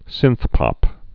(sĭnthpŏp)